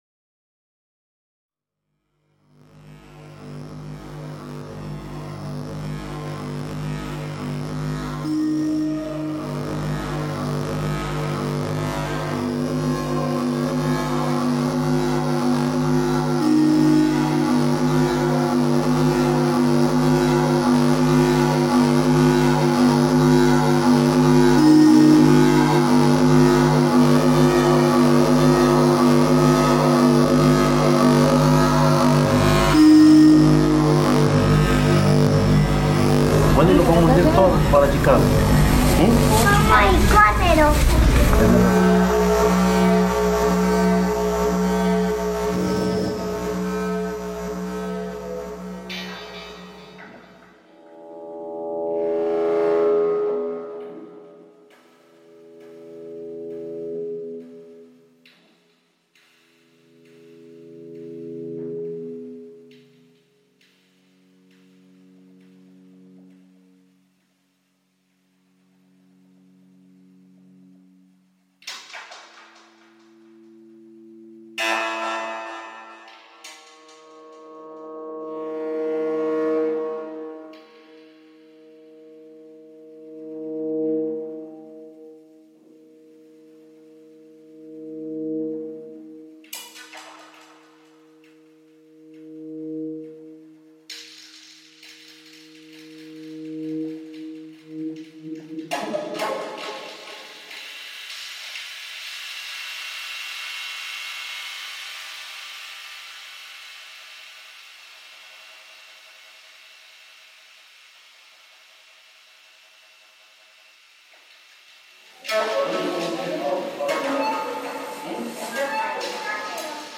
El Paso airport recording reimagined